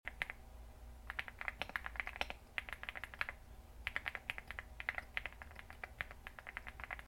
Creamy keyboard typing for tingles sound effects free download